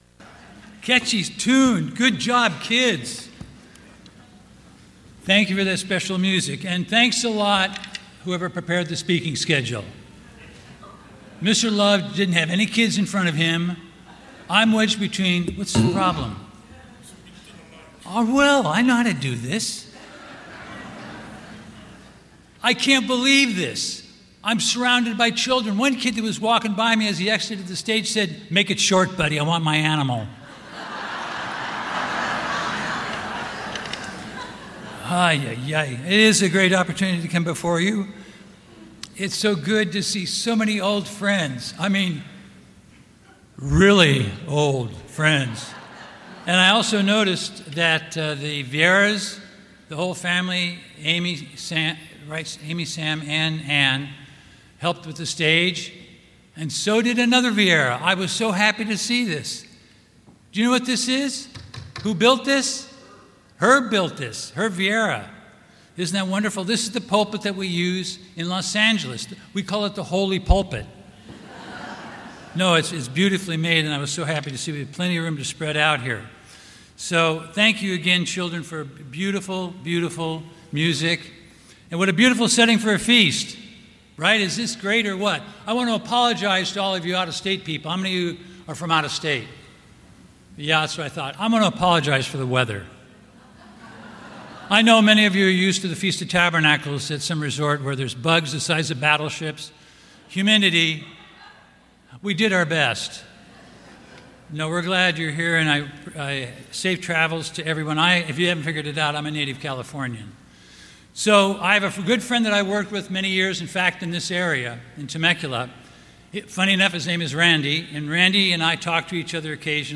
Sermons
Given in Temecula, California